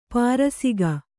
♪ pārasiga